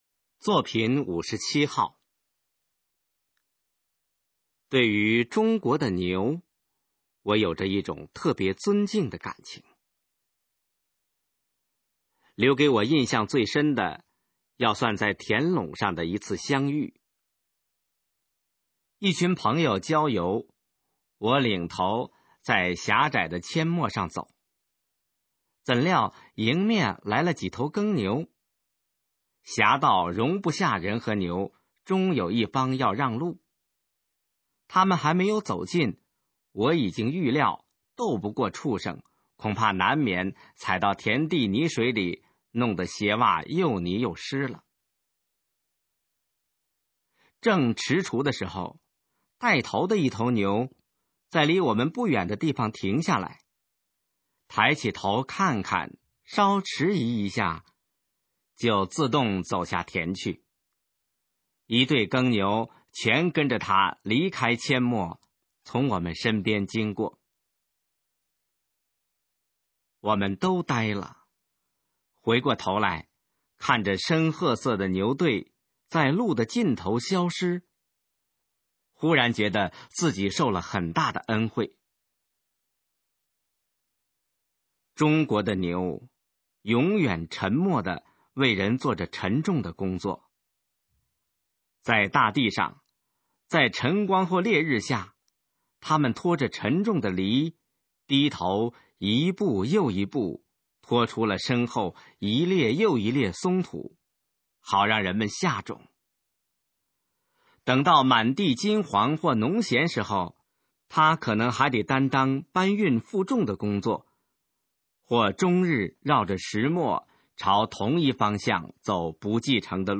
首页 视听 学说普通话 作品朗读（新大纲）
《中国的牛》示范朗读